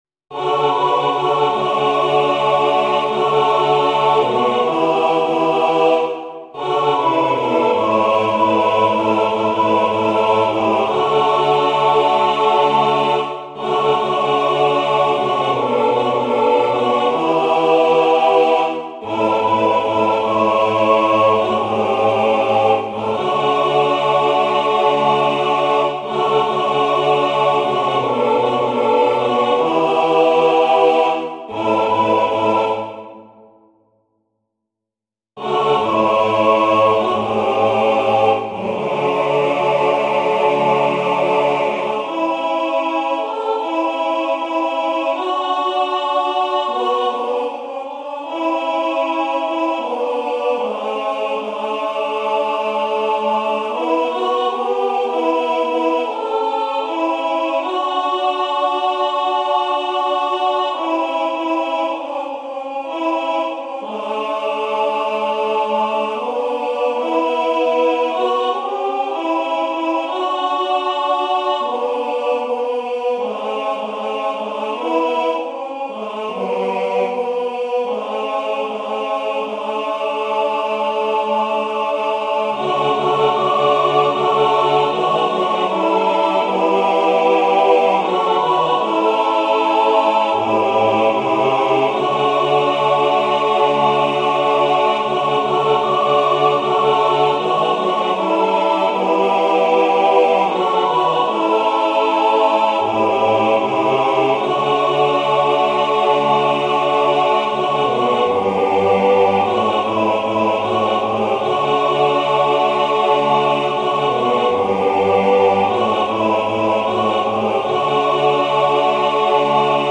Chants de Requiem Téléchargé par